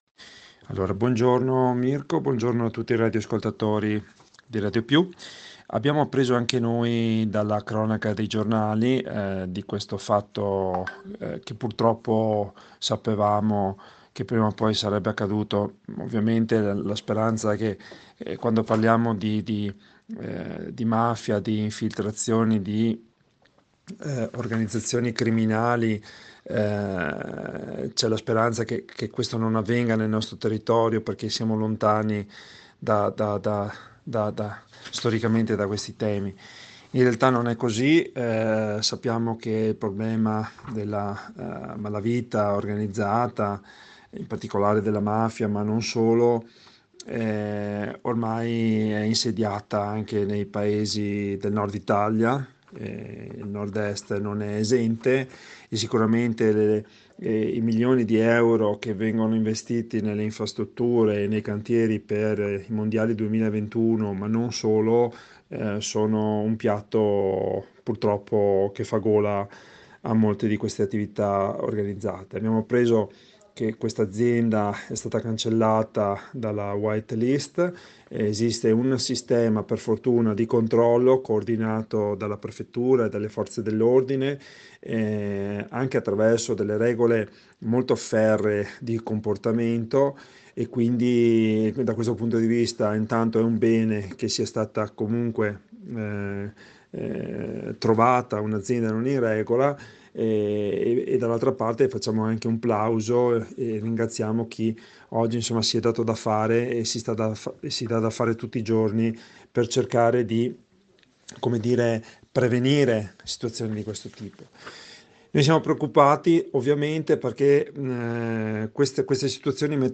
DAL NOTIZIARIO DELLE 9.30